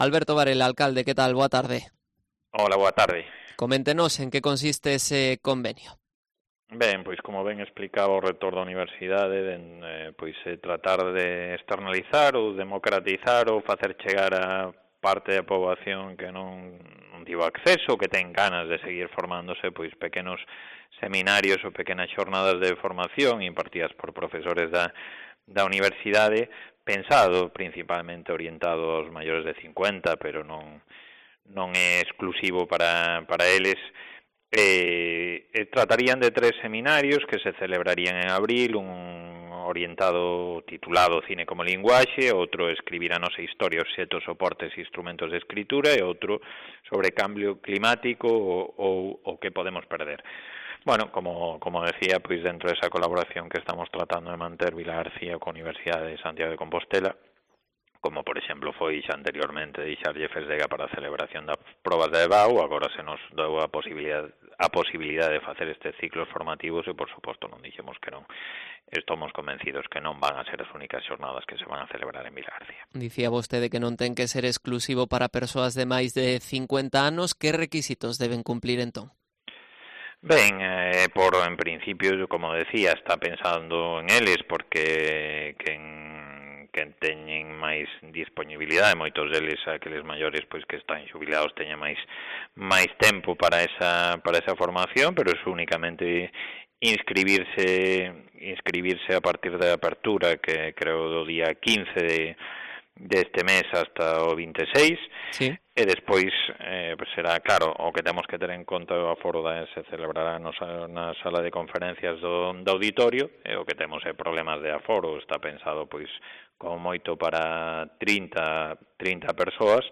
Entrevista a Alberto Varela, alcalde de Vilagarcía de Arousa